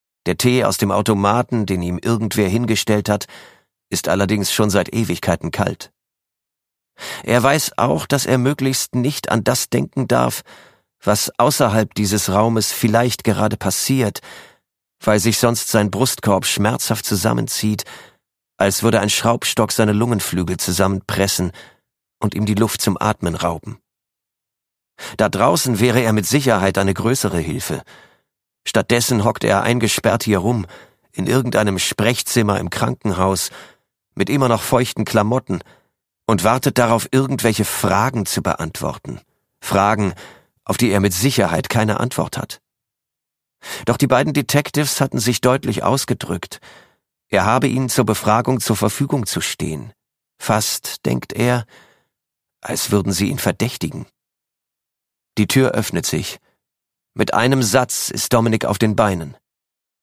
Produkttyp: Hörbuch-Download